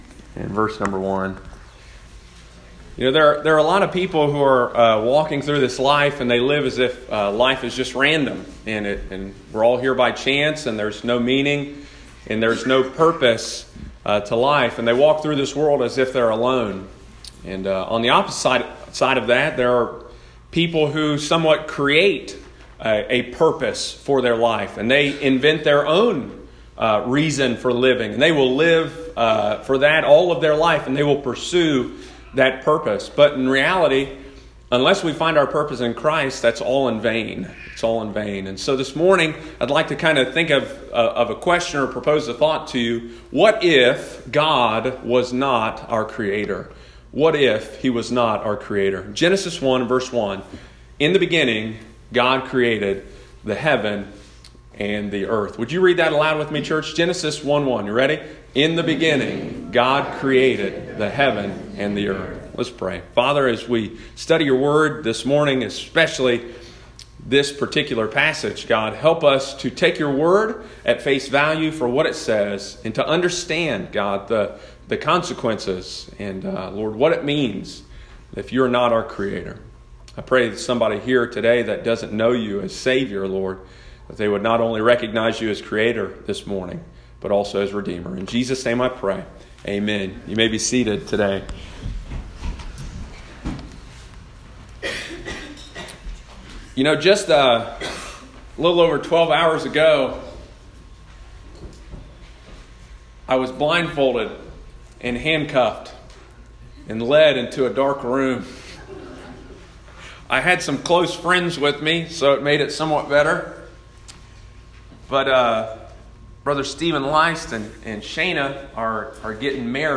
Sunday morning, April 7, 2019.